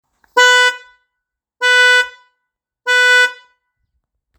Fanfáry 12V 2-tonový
Jednotlivé tony pokud se použije pouze jedna nebo druhá fanfára.